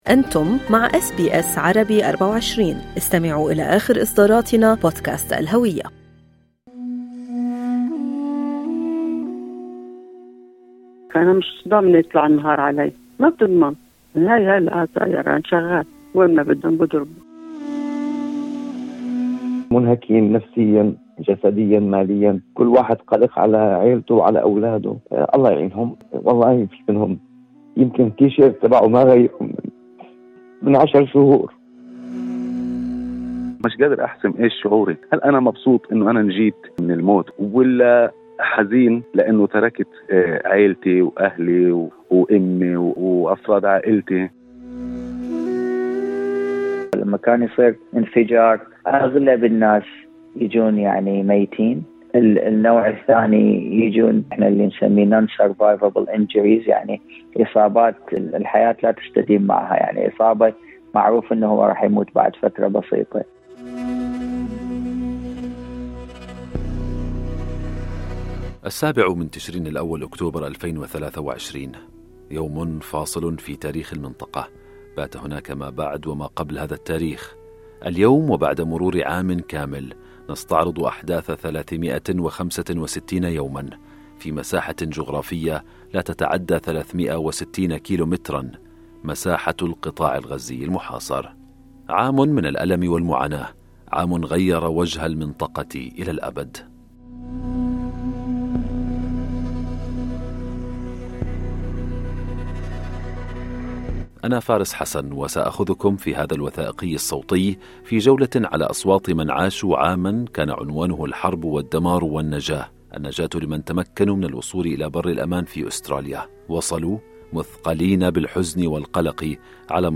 بالتزامن مع الذكرى السنوية الأولى لهجمات الفصائل الفلسطينية على جنوب إسرائيل والذي أودى بحياة ألف ومئتي شخص فضلاً عن أخذ 250 رهينة يُعتقد أن 100 منهم ما زالوا في قطاع غزة، والحرب المدمرة التي شنها الجيش الإسرائيلي في القطاع، نستمع في هذا التقرير إلى أصوات من عاشوا عاماً كان عنوانه الحرب والدمار.